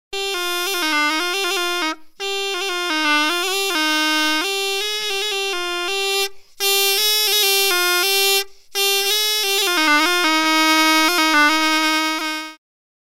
URM Sonos de Sardigna :Rielaborazioni - Bena de ottone